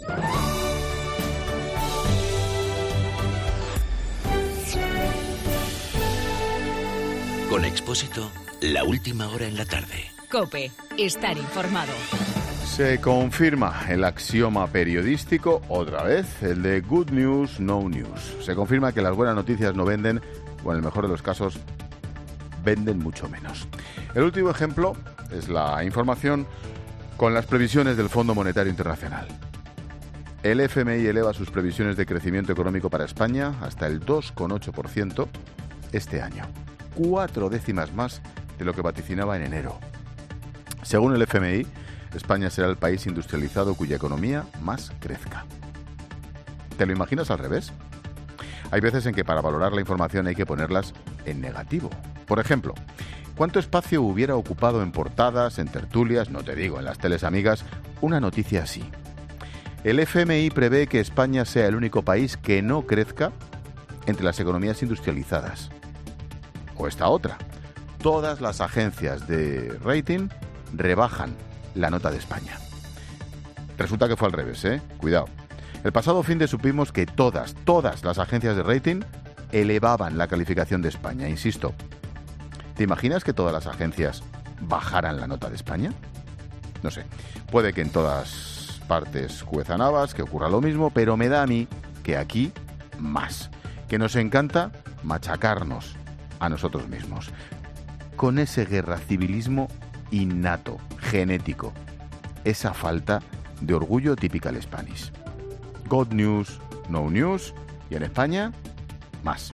Monólogo de Expósito
El comentario de Ángel Expósito por los buenos datos del FMI sobre el crecimiento de España.